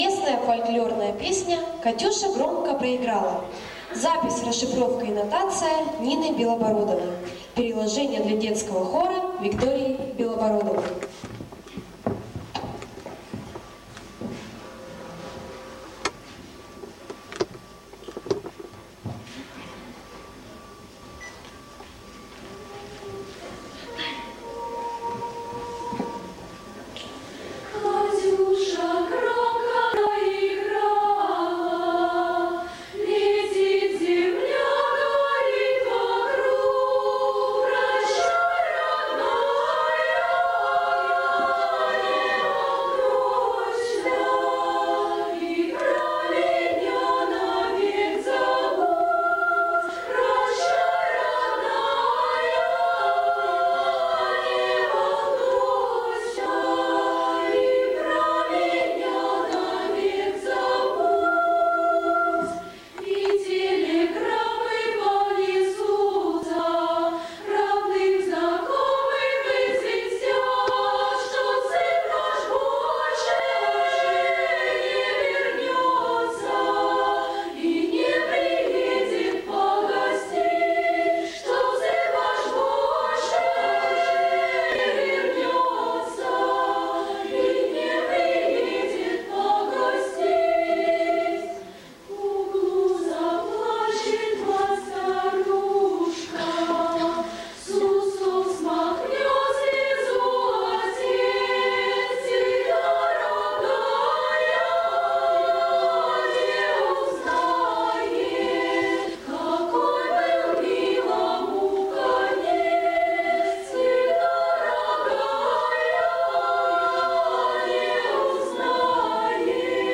Исполняет хор «Ровесник» МУДО Усть-Ордынская ДШИ
Катюша" горомко проиграла (военная), 2010 г.